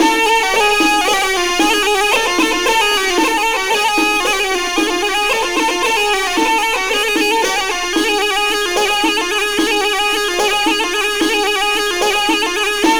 DRUM+PIPE1-L.wav